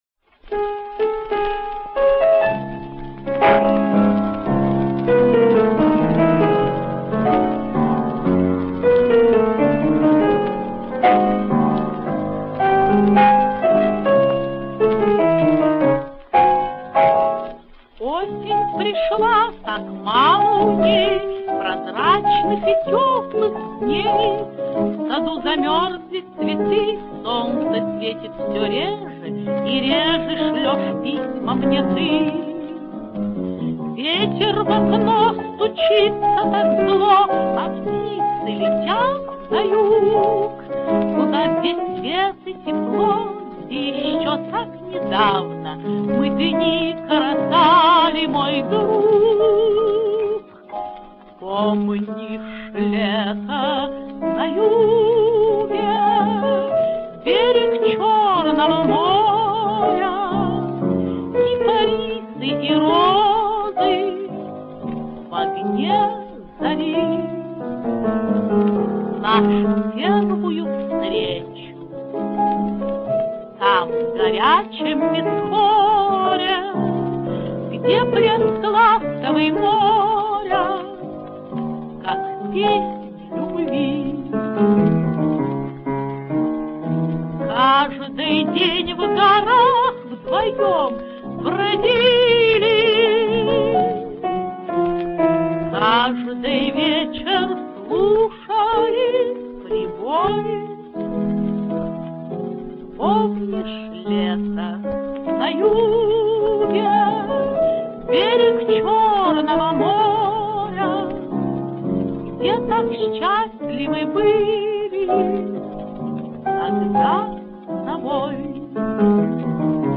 Ленинград, 1939 год.